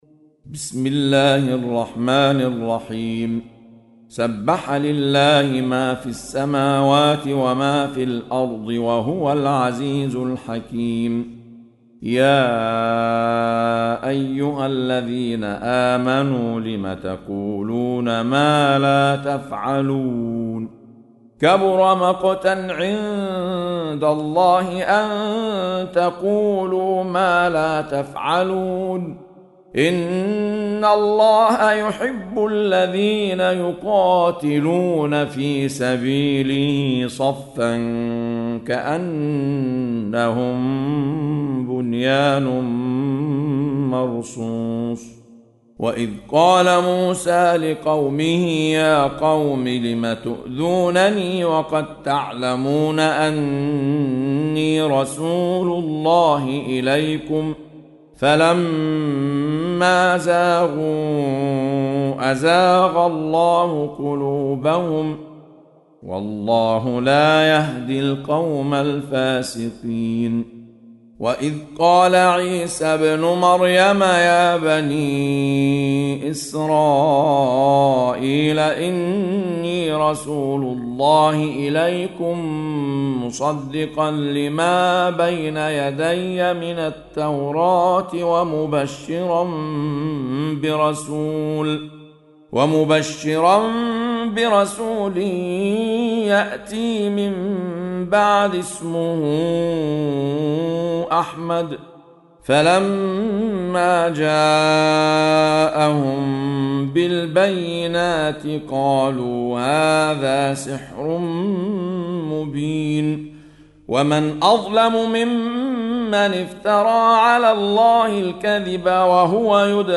سورة الصف | القارئ أحمد عيسي المعصراوي